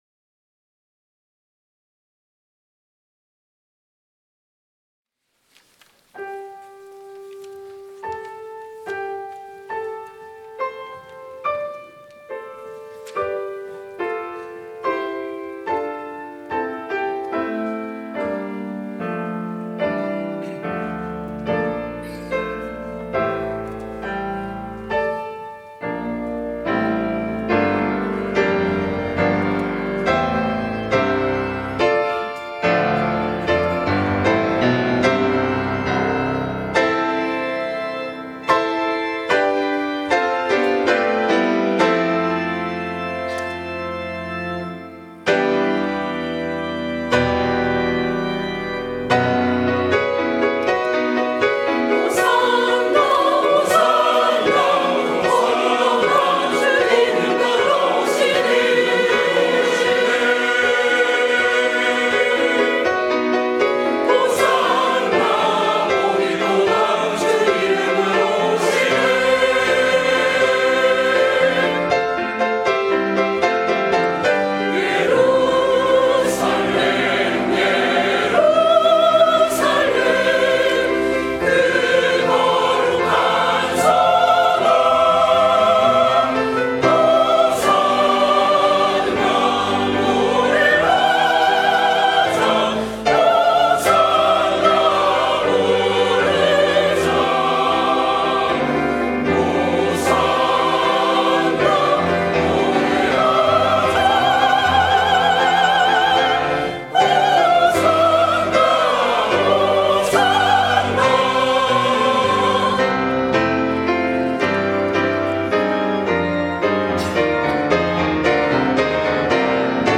종려주일 찬송